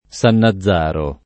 San nazz#ro] top. — es.: San Nazzaro Sesia [